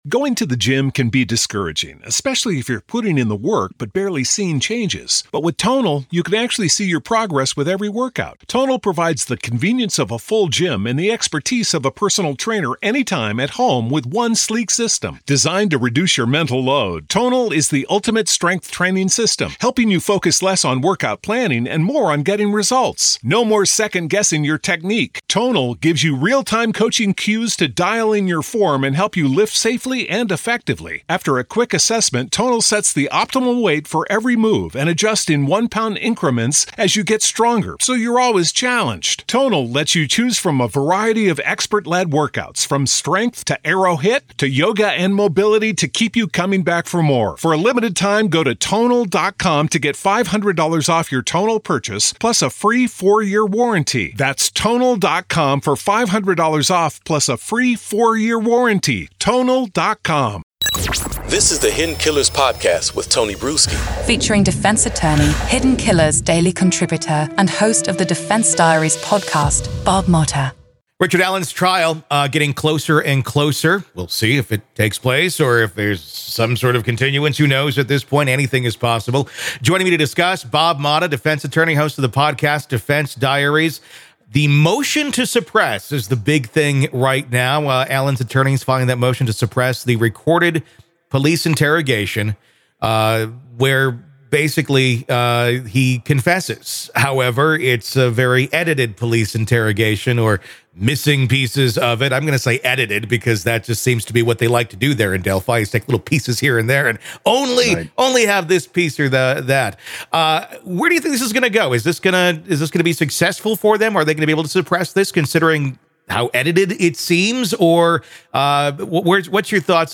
Main Points from the Conversation - **Motion to Suppress**: Allen’s defense has filed multiple motions to suppress, focusing on a police interrogation that was edited and other confessions coerced by correctional officers and inmates.